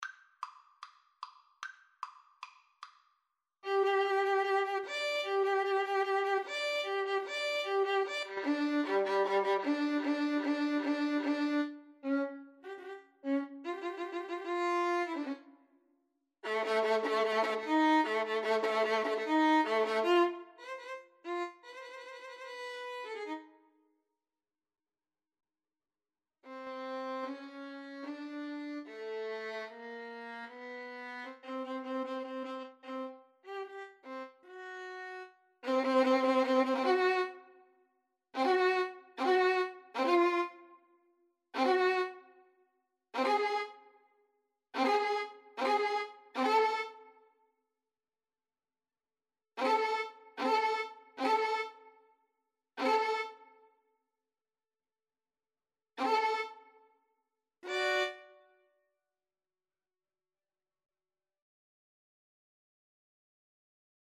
Classical
= 150 Allegro Moderato (View more music marked Allegro)
4/4 (View more 4/4 Music)